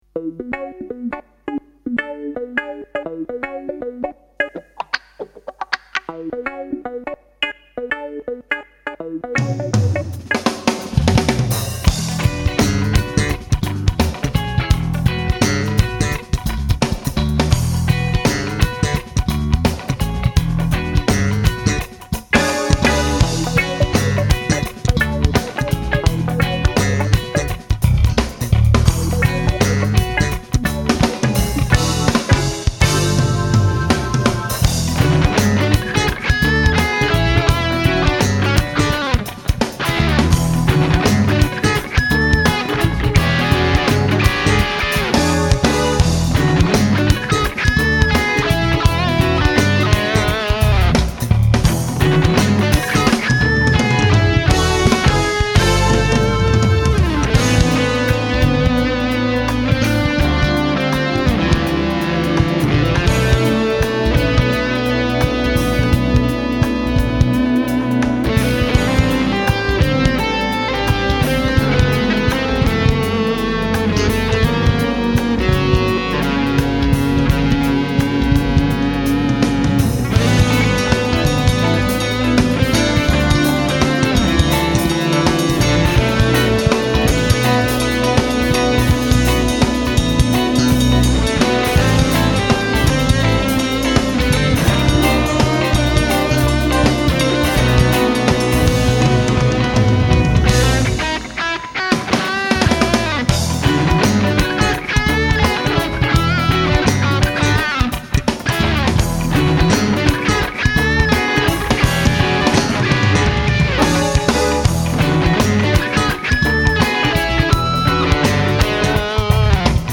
enregistré en juin '01 au studio Taurus à Genève.
Basse
Guitare rythmique 1
Guitare solo
Clavier
Batterie